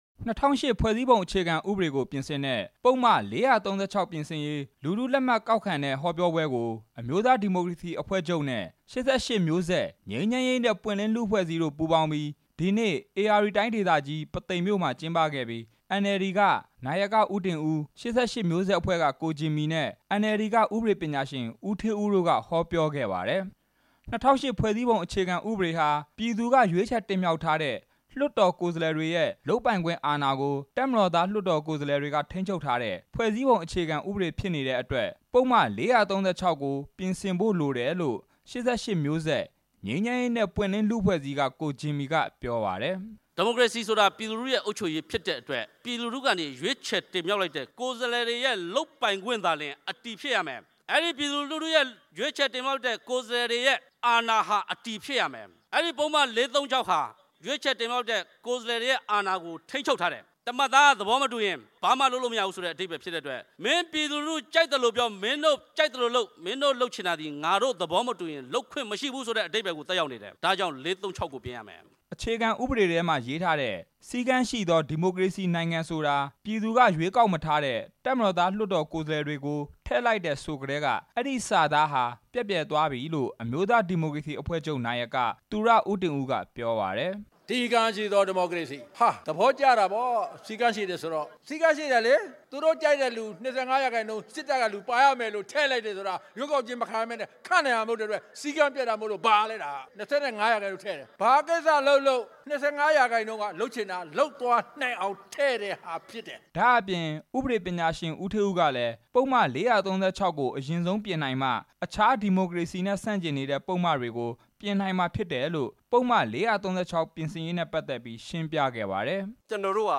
ဒီကနေ့ ဧရာဝတီတိုင်း ပုသိမ်မြို့ ကိုးသိန်း အားကစားကွင်းမှာ အမျိုးသားဒီမိုကရေစီအဖွဲ့ချုပ်နဲ့ ၈၈ မျိုးဆက် ငြိမ်းချမ်းရေးနဲ့ပွင့်လင်းလူ့အဖွဲ့အစည်းတို့ ပူးပေါင်းကျင်းပတဲ့ ပုဒ်မ ၄၃၆ ပြင်ဆင်ရေး လူထုသဘော ထား ကောက်ခံပွဲမှာ သူရဦးတင်ဦး က အခုလို ပြောလိုက်တာပါ။